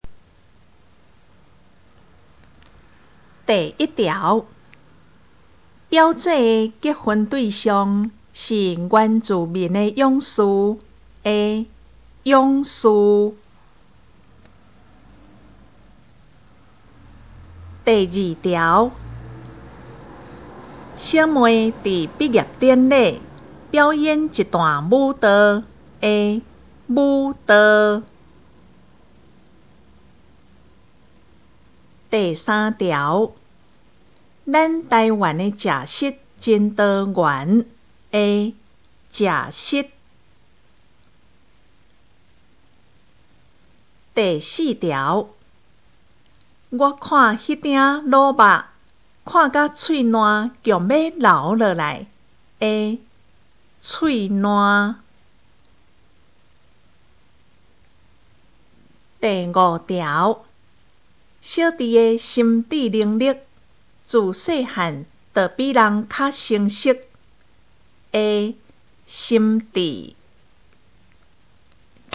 【國中閩南語4】每課評量(5)聽力測驗mp3